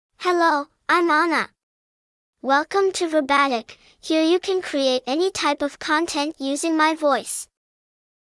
Ana is a female AI voice for English (United States).
Voice sample
Listen to Ana's female English voice.
Female
Ana delivers clear pronunciation with authentic United States English intonation, making your content sound professionally produced.